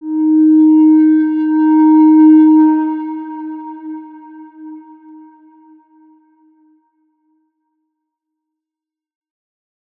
X_Windwistle-D#3-mf.wav